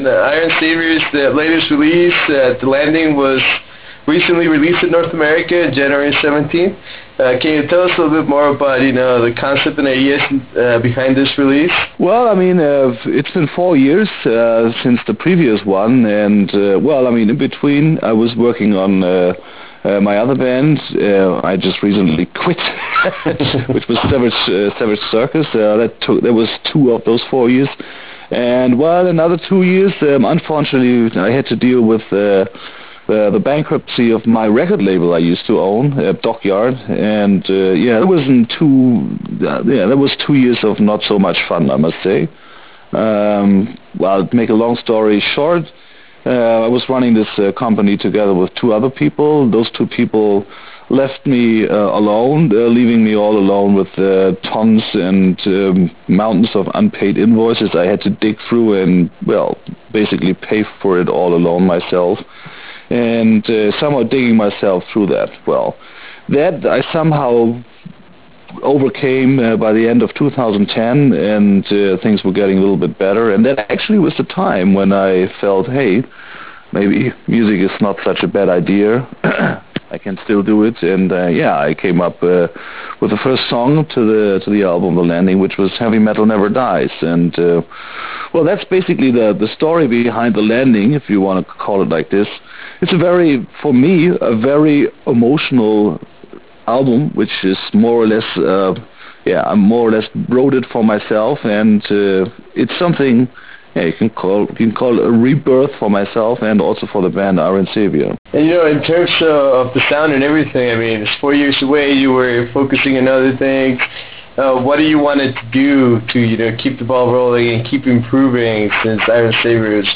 In this interview we talk about the 4 year gap between releases and all the events that led to "The Landing".